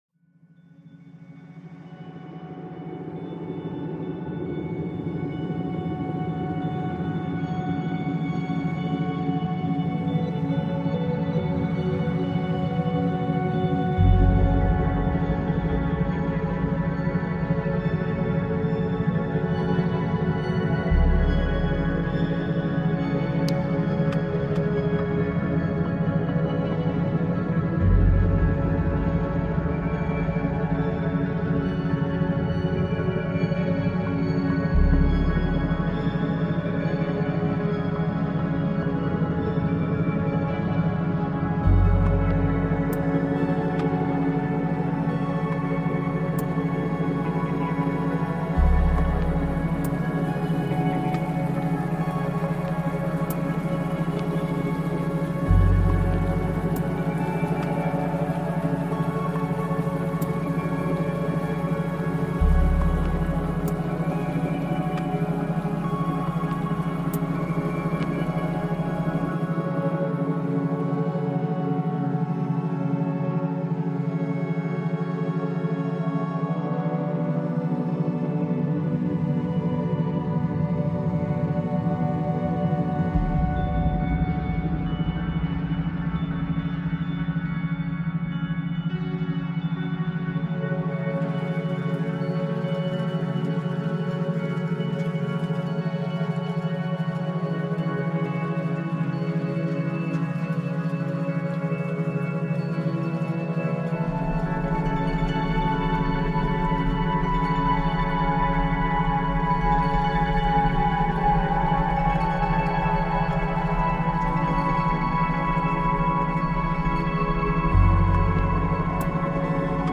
Pluie nocturne apaisante · méthode d’ambiance éprouvée pour étude paisible